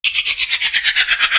spider_dies.ogg